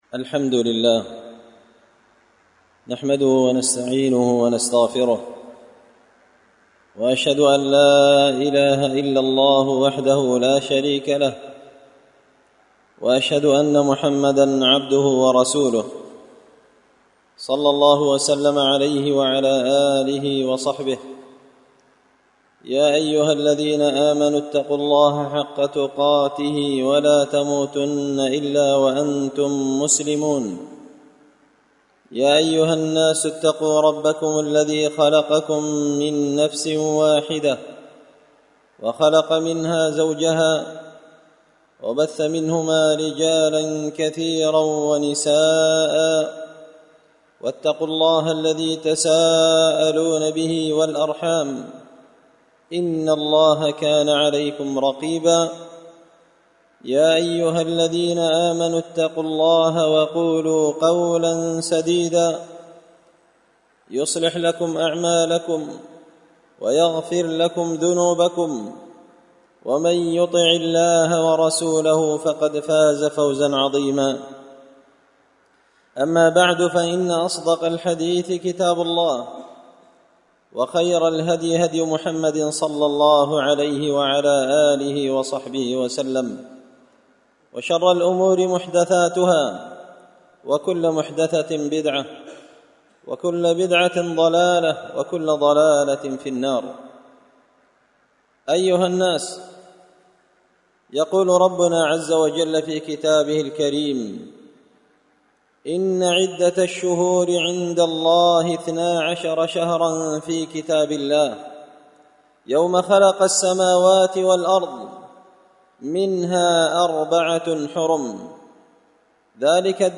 خطبة جمعة بعنوان – عاشوراء
دار الحديث بمسجد الفرقان ـ قشن ـ المهرة ـ اليمن